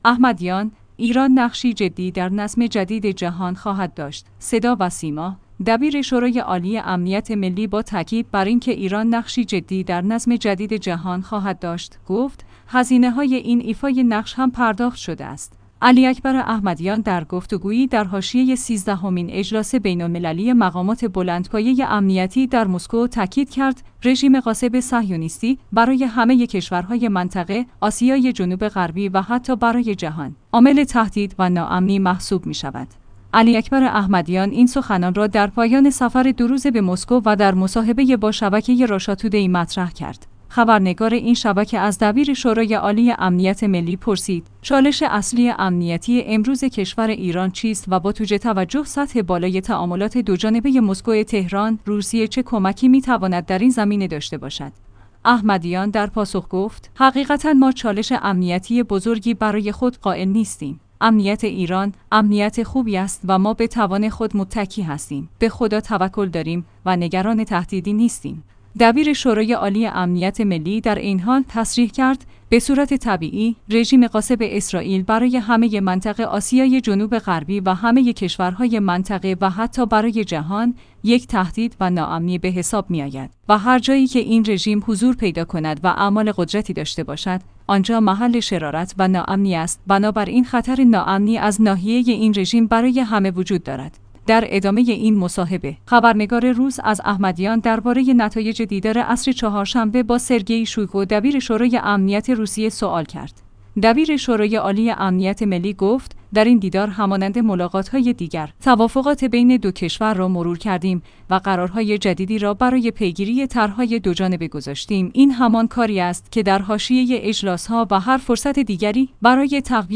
علی‌اکبر احمدیان در گفت‌وگویی در حاشیه سیزدهمین اجلاس بین‌المللی مقامات بلندپایه امنیتی در مسکو تأکید کرد: رژیم غاصب صهیونیستی، برای همه کشورهای منطقه،